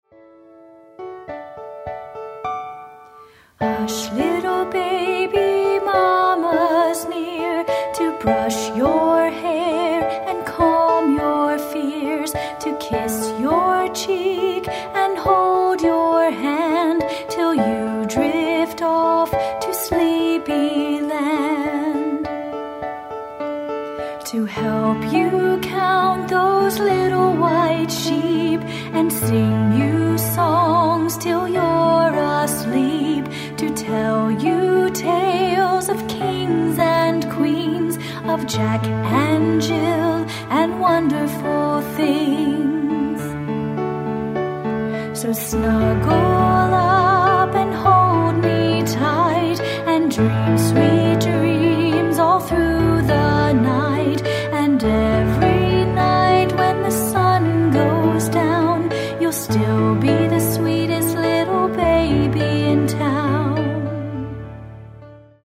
hot ‘n’ pumpy, clean and pristine, naturally ambient, “created” ambience,
dry studio, lower-fi retro-sound, open-air vs. brick wall mastering.